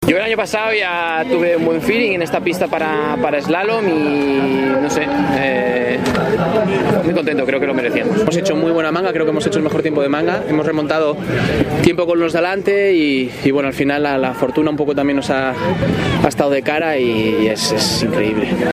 según expresaron, al alimón formato MP3 audio(0,14 MB), tras recoger la plata que volvía a colgar de su cuello, sin ningún atisbo de superstición el martes día 13, en la supercombinada, compuesta por una manga de supergigante y otra de eslalon.